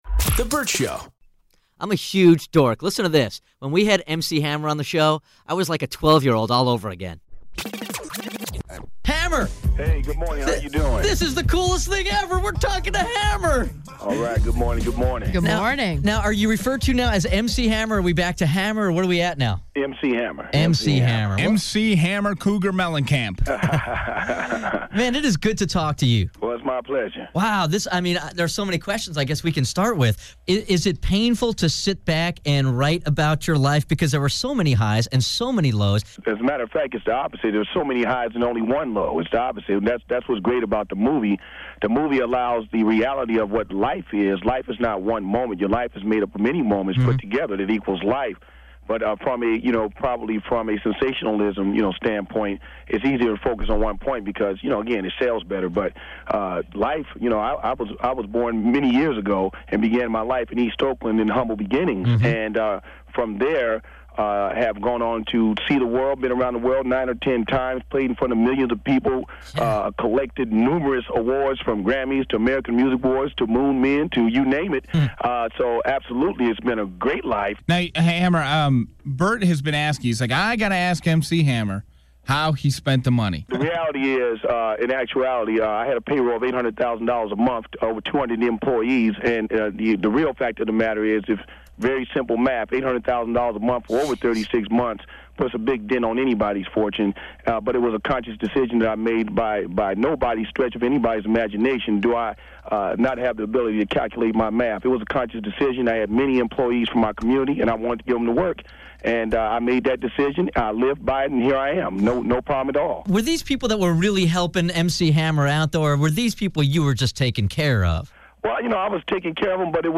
Vault: Interview With MC Hammer